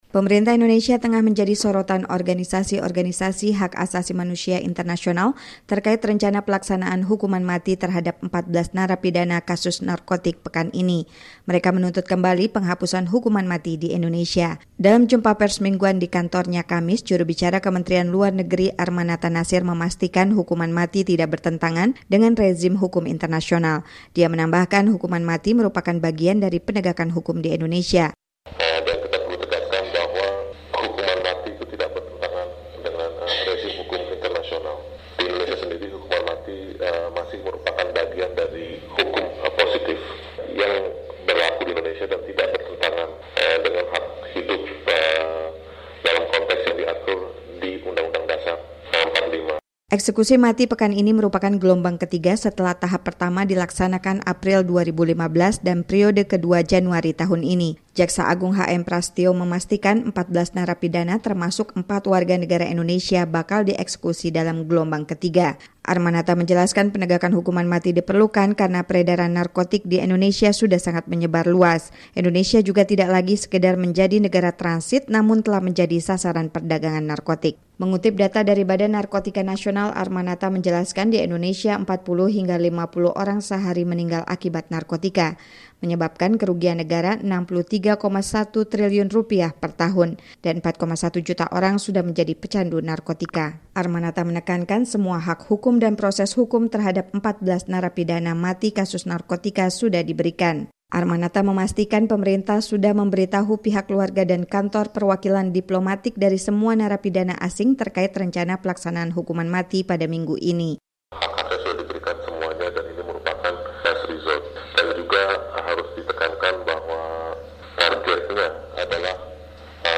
Dalam jumpa pers mingguan di kantornya, Kamis (28/7), juru bicara Kementerian Luar Negeri Arrmanatha Nasir memastikan hukuman mati tidak bertentangan dengan rezim hukum internasional.